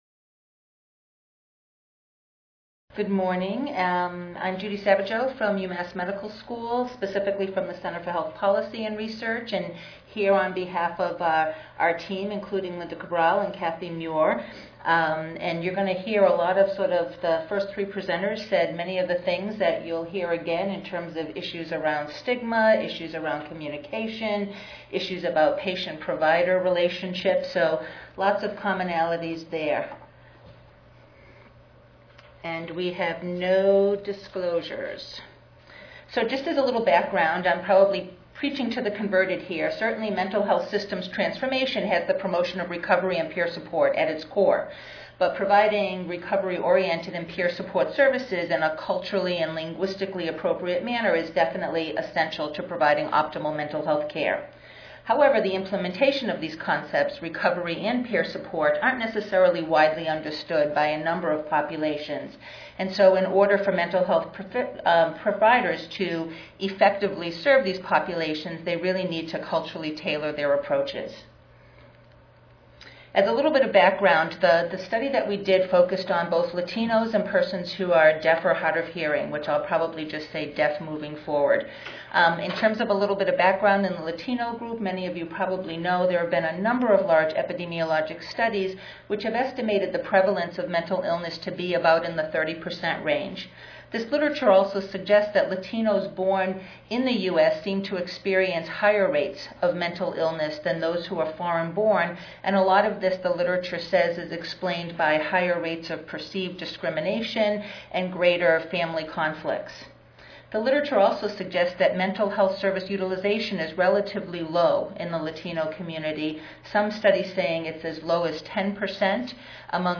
5139.0 Bruno Lima symposium: Latino mental health Wednesday, November 2, 2011: 10:30 AM Oral This session presents topics on Latino mental health. It describes the process and outcomes of a behavioral health needs assessment in a Latino community.